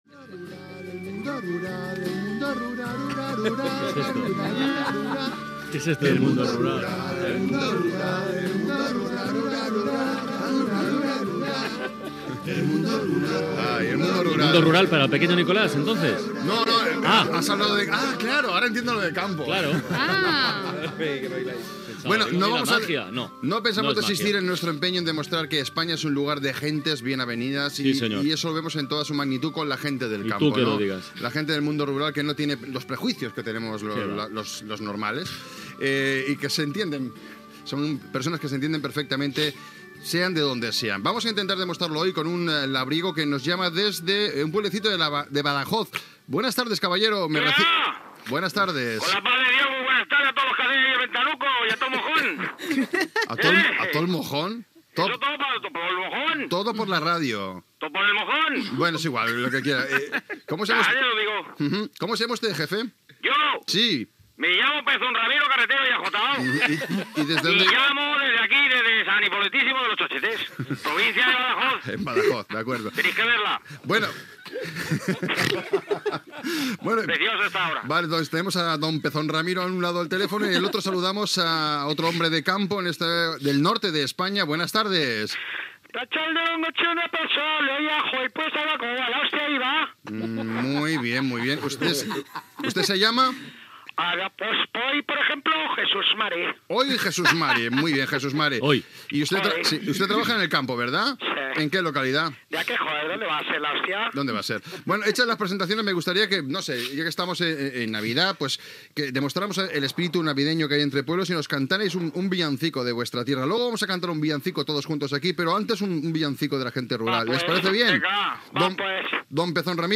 Los Especialistas Secundarios "El mundo rural" amb nadales humorístiques
Entreteniment